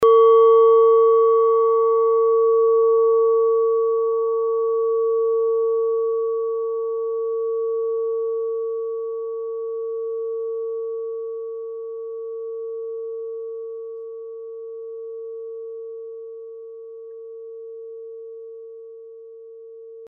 Nepal Klangschale Nr.1 Planetentonschale: Eros-Ton
Klangschale Nepal Nr.1
(Ermittelt mit dem Filzklöppel oder Gummikernschlegel)
klangschale-nepal-1.mp3